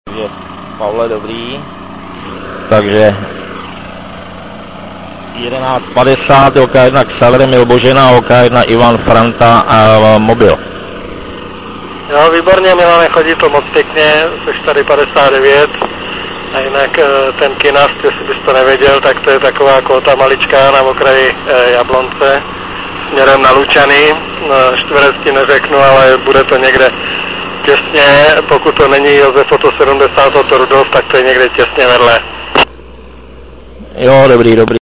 Nahrávky nejsou nejkvalitnější.
Nahrávku jsem musel dělat tak, že jsem "špuntové" sluchátko přikládal na mp3 nahravač.